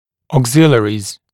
[ɔːg’zɪlɪərɪz][о:г’зилиэриз]дополнительные приспособления